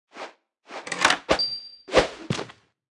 Media:Sfx_Anim_Ultimate_Archer Queen.wav 动作音效 anim 在广场点击初级、经典、高手、顶尖和终极形态或者查看其技能时触发动作的音效
Sfx_Anim_Ultimate_Archer_Queen.wav